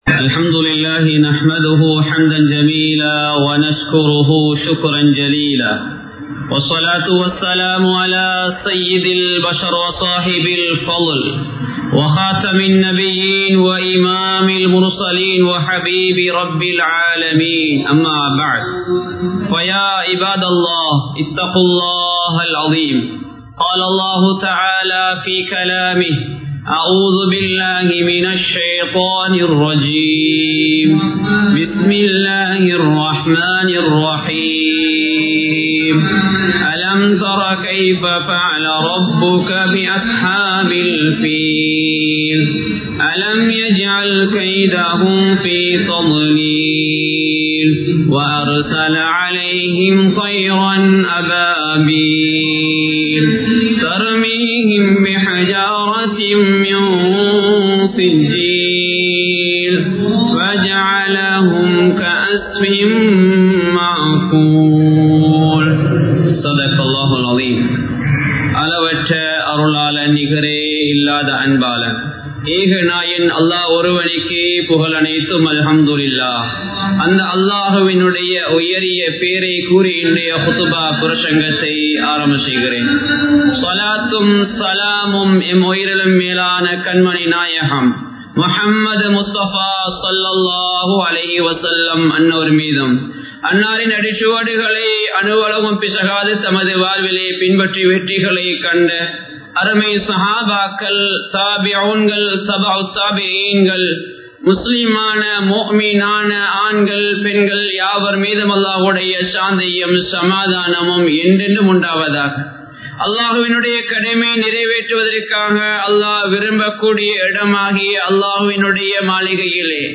மக்கா & மதீனாவின் சிறப்புகள் | Audio Bayans | All Ceylon Muslim Youth Community | Addalaichenai
Colombo 03, Kollupitty Jumua Masjith